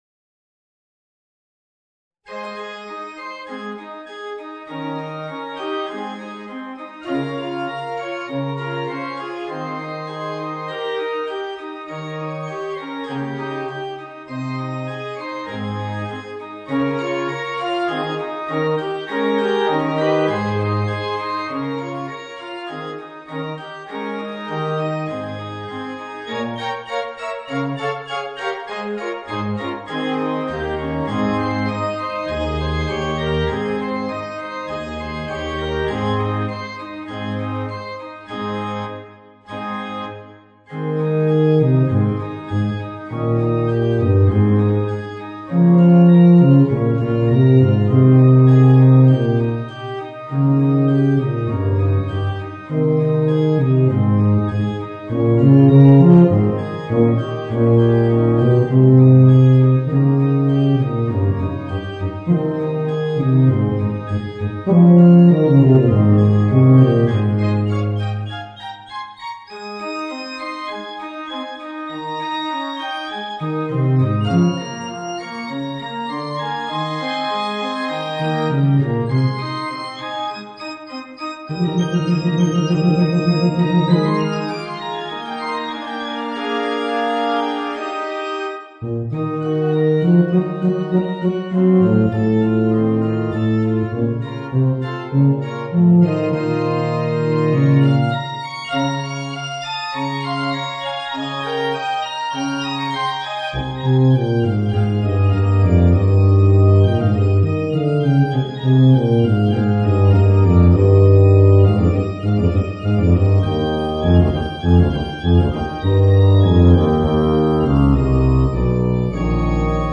Bb Bass and Organ